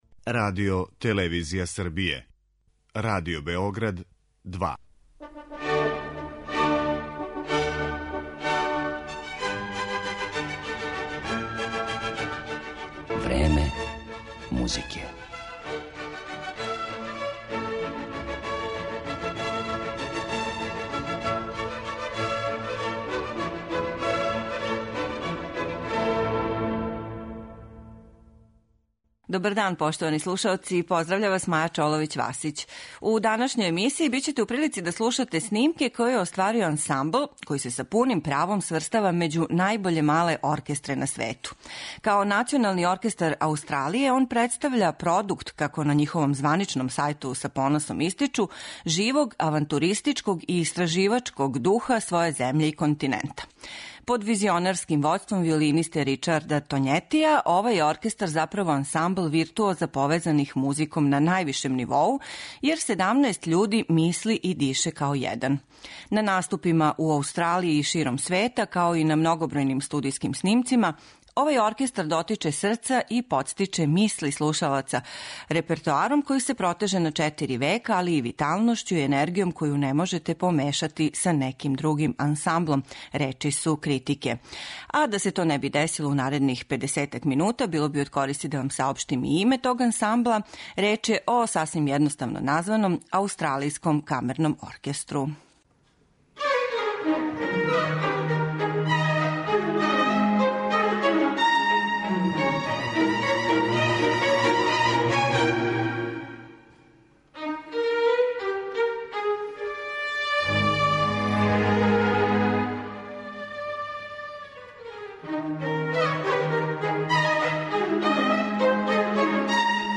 Под управом виолинисте Ричарда Тоњетија, Аустралијски камерни оркестар изводи дела Јаначека, Баха, Моцарта, Грига, Вивалдија ...